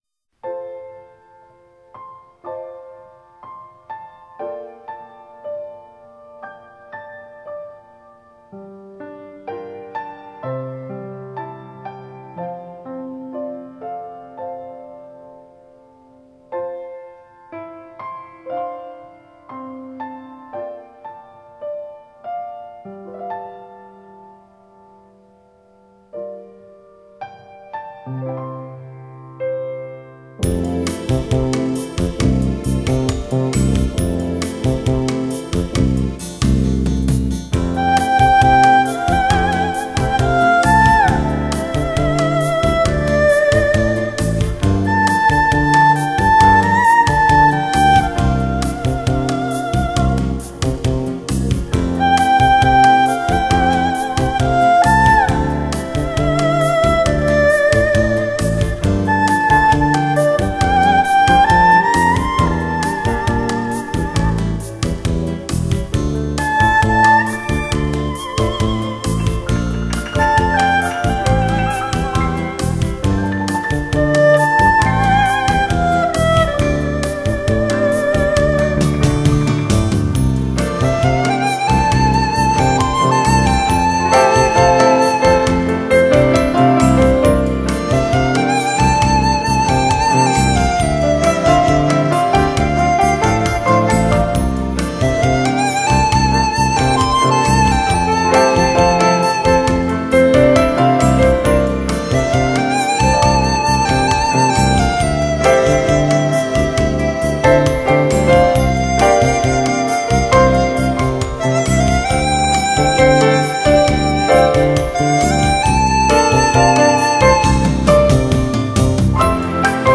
崭新风格的新体验，灵气迫人，悠雅怡然的，清新民乐精品。
碟中音色轻柔细腻、圆润质朴、深情委婉、旋律优美。
被乐手们演奏得激情十足，使得整套系列充满强烈的时代感和现代感。